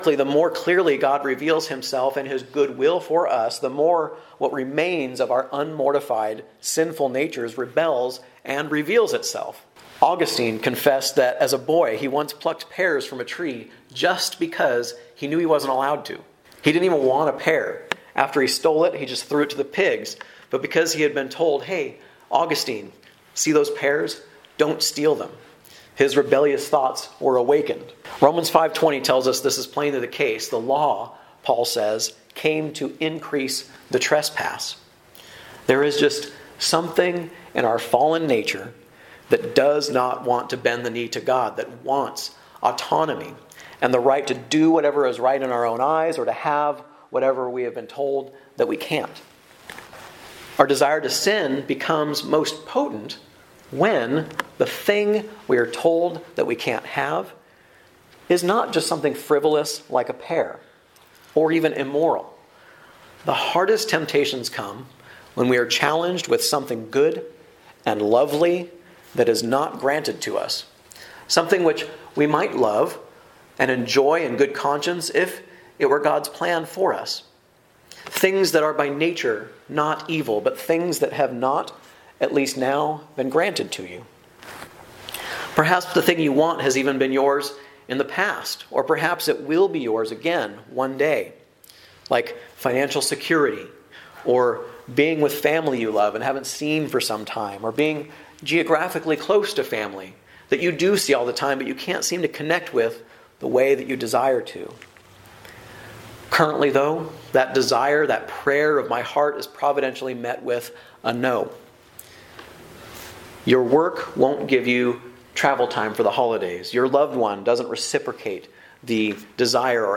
A message from the series "Guest Preacher."